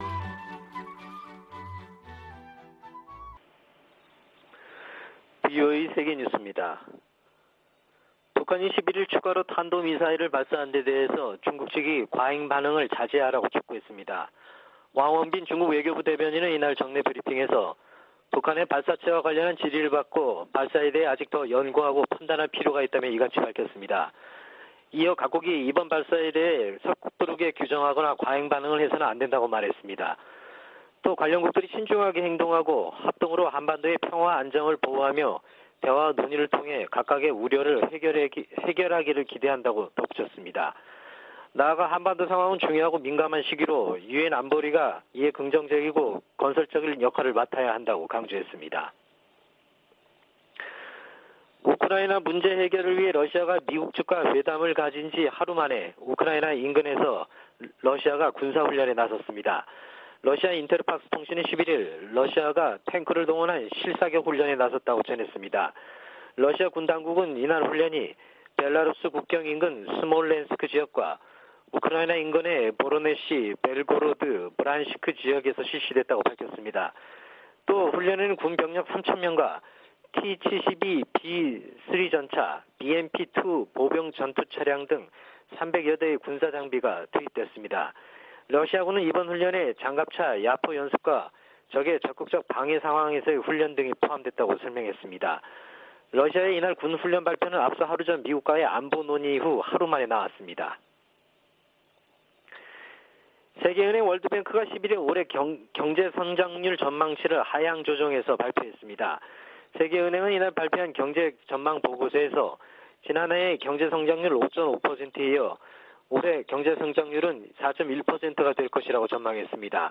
VOA 한국어 아침 뉴스 프로그램 '워싱턴 뉴스 광장' 2021년 1월 12일 방송입니다. 북한이 엿새 만에 또 다시 탄도미사일 추정체를 발사했습니다. 유엔 안보리가 지난 5일의 북한 탄도미사일 발사 문제를 논의했습니다. 미 국방부는 극초음속 미사일을 발사했다는 북한의 주장에 세부사항을 평가 중이라고 밝혔습니다.